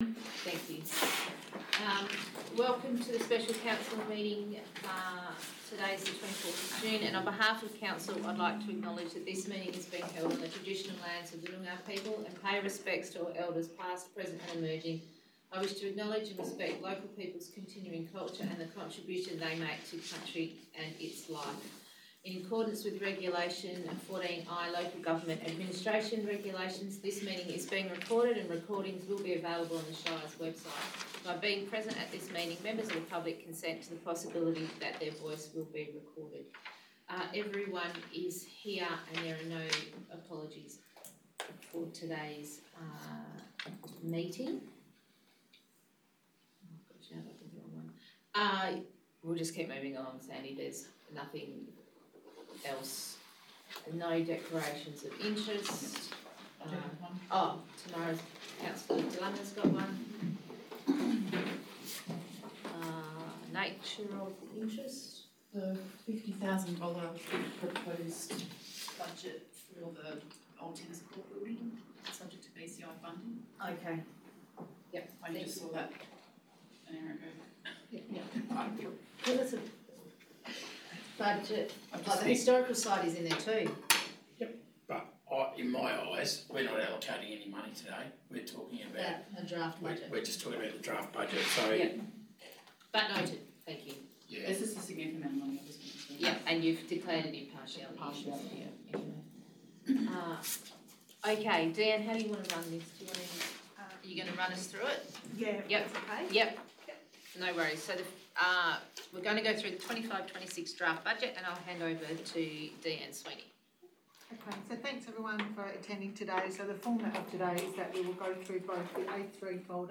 24 June 2025 - Special Meeting of Council » Shire of Brookton